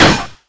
rifle_hit_metal3.wav